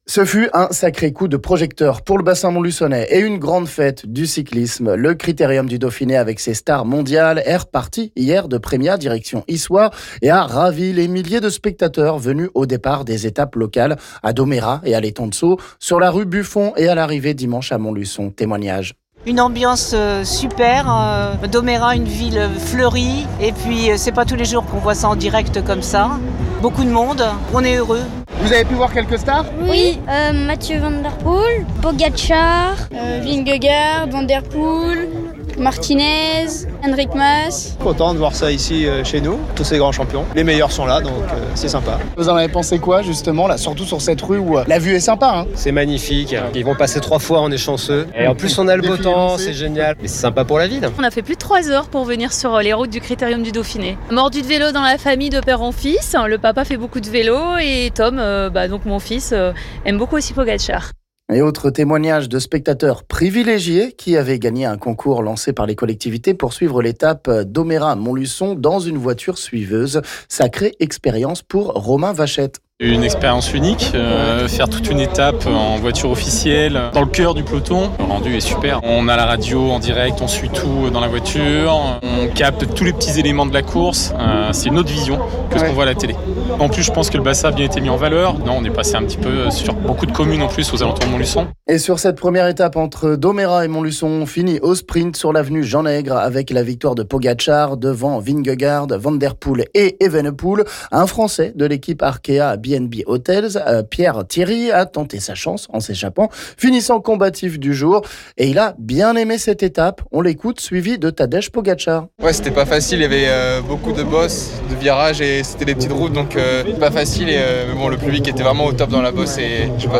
Des milliers de spectateurs dans une ambiance incroyable, des favoris au rendez-vous du défi sportif dans un peloton de pros très disponible pour les fans, ravis, sans oublier le coup de projecteur sur le bassin montluçonnais, le tout sous le soleil…Ces 2 1ères étapes du Critérium du Dauphiné, auront vraiment été une réussite, et laisseront des souvenirs mémorables, dont des photos à retrouver sur nos réseaux, et interviews à écouter ici...